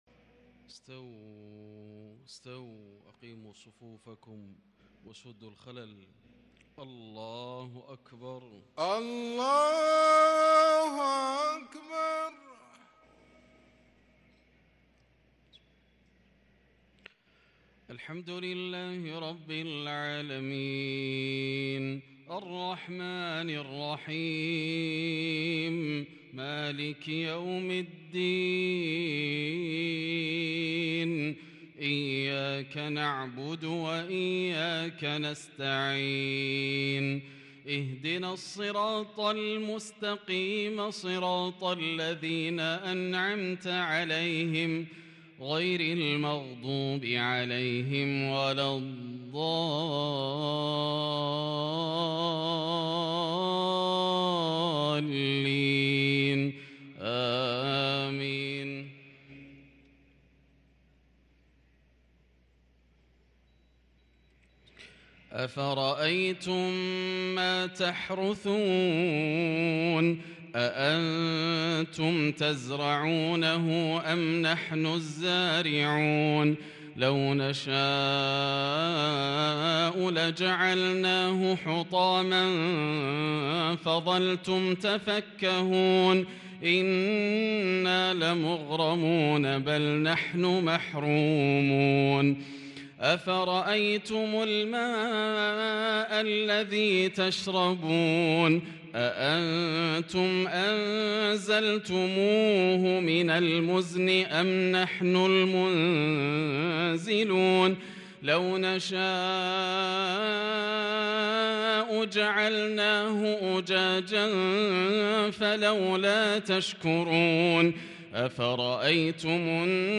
صلاة العشاء للقارئ ياسر الدوسري 16 جمادي الأول 1444 هـ
تِلَاوَات الْحَرَمَيْن .